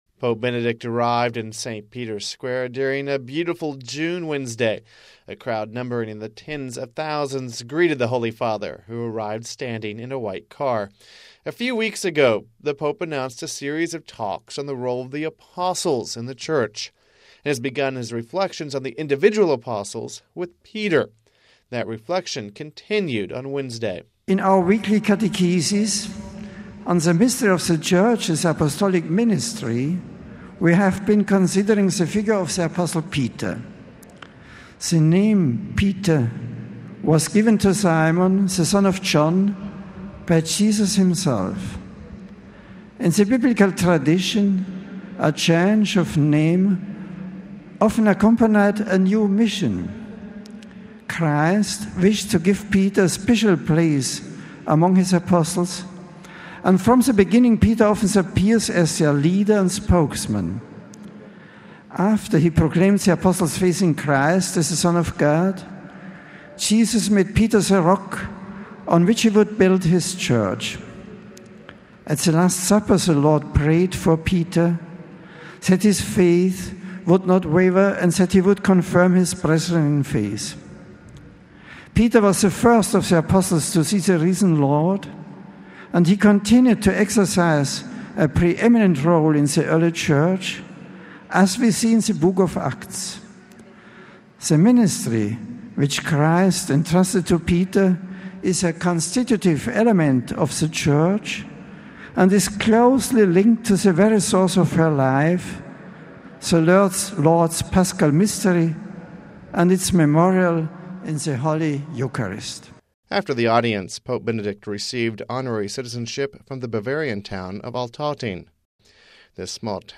Home Archivio 2006-06-07 15:41:46 Weekly General Audience (07 June 06 - RV) Pope Benedict continued his catechesis on Saint Peter during his weekly general audience.